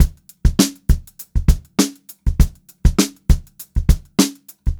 100STBEAT1-L.wav